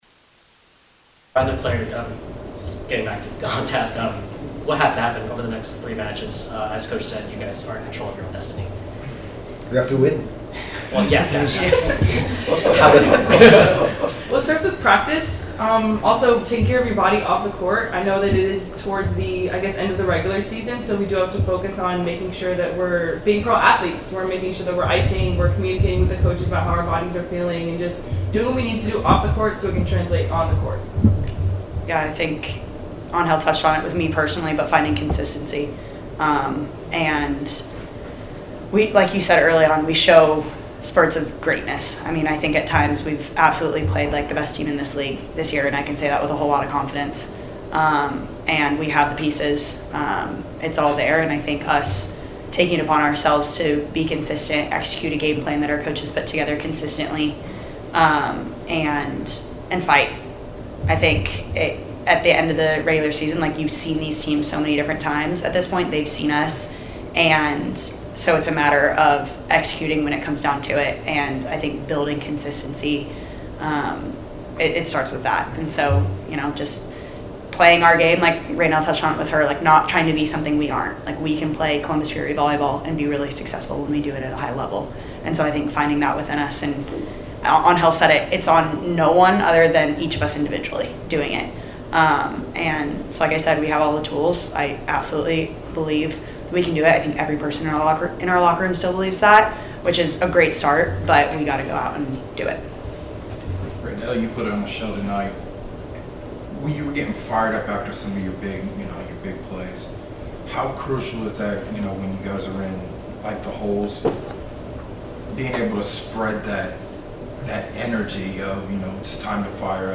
Match Recap: Fury Come Up Short Against Omaha; Postmatch News Conference